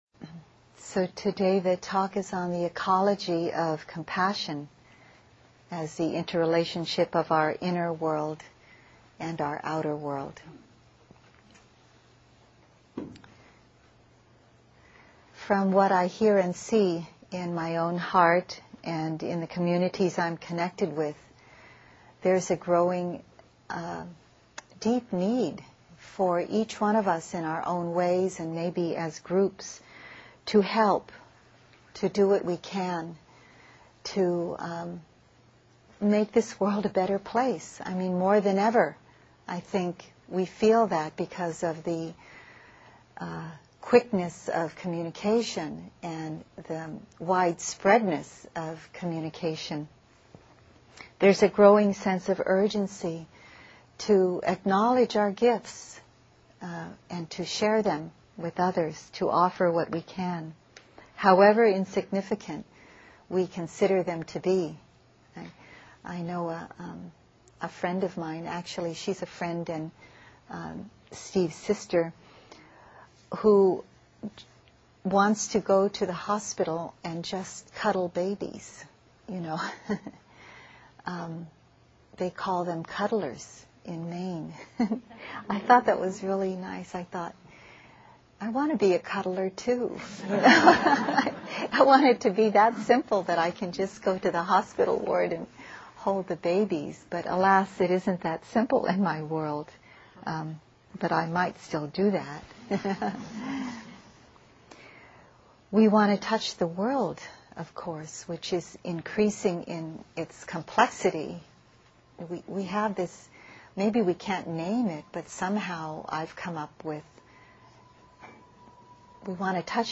This talk was given at a non-residential retreat in Jan 2007.